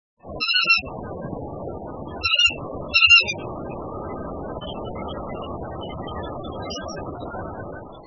2124h「鳥の鳴声」
〔ケリ〕キリリ，キリリ／草原などで繁殖，普通・留鳥，34p，雌雄夏冬同色